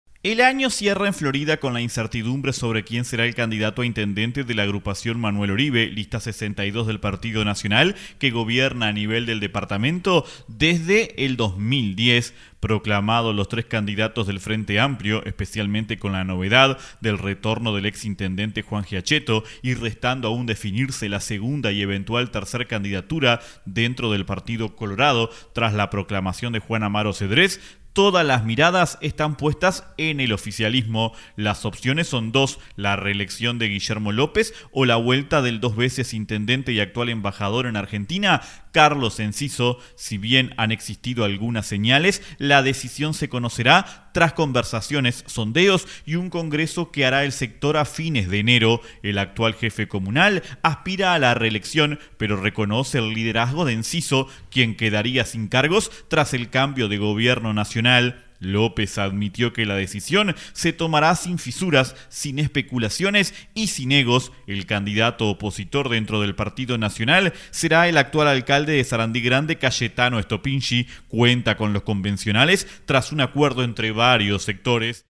El informe del corresponal para 970 Noticias